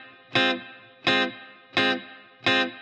DD_TeleChop_85-Fmin.wav